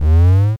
Jump3.mp3